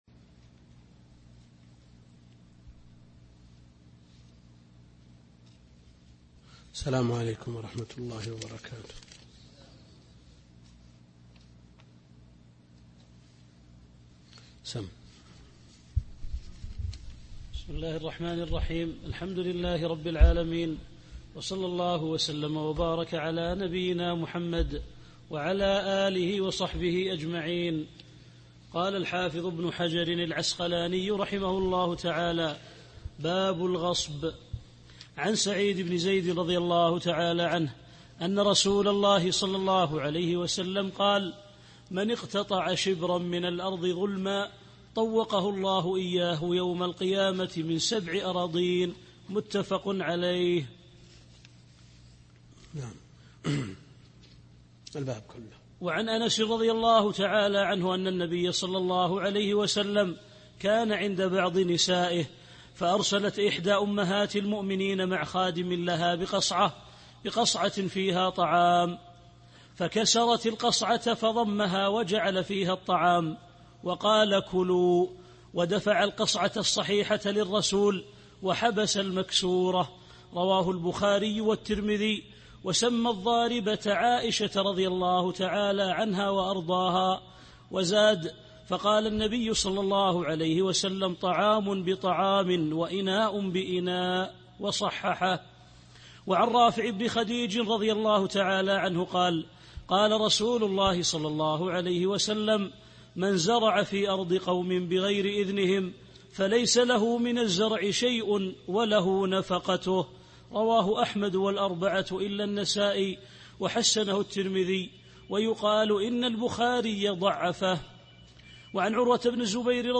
الدرس الخامس عشر من دروس شرح بلوغ المرام كتاب البيوع